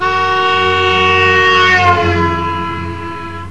meuh.aiff